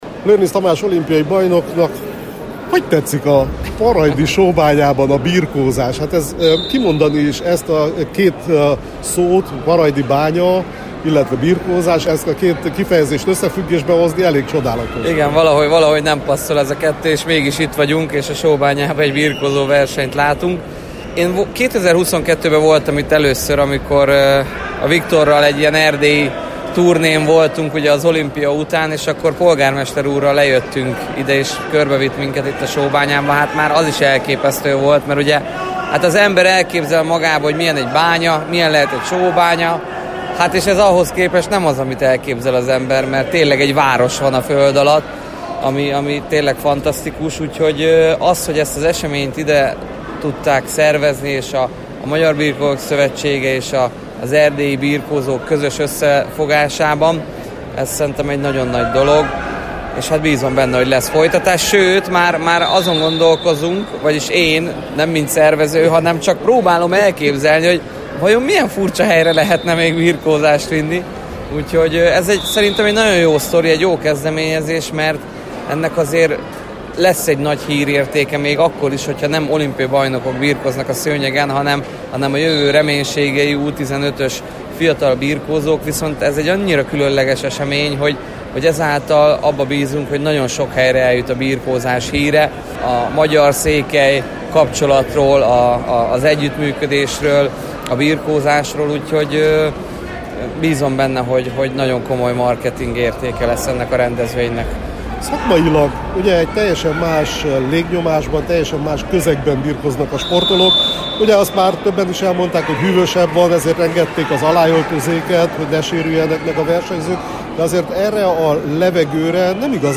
Lőrincz Tamás olimpiai bajnok birkózó, aki jelenleg a KIMBA szakmai igazgatója a verseny szerepéről, az olimpia útról beszélt riporterünknek: